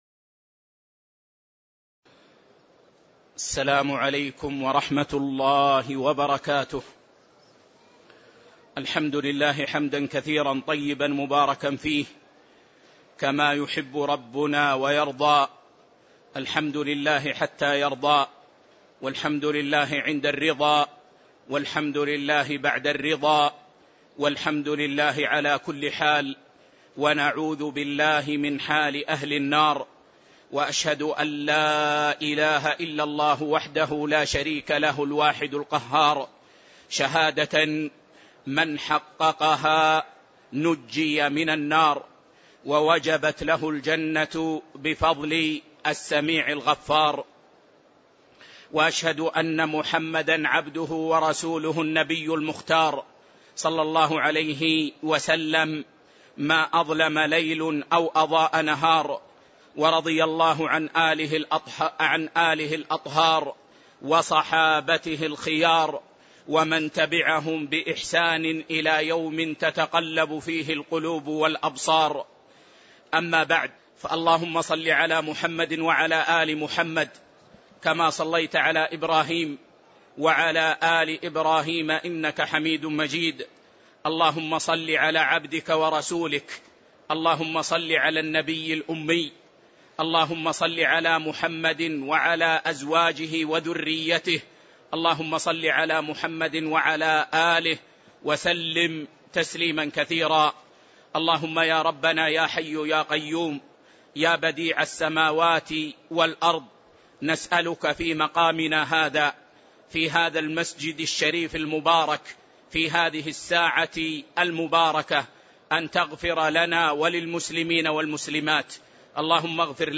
تاريخ النشر ٢٣ جمادى الآخرة ١٤٣٧ هـ المكان: المسجد النبوي الشيخ